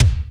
Kick Metal X5.wav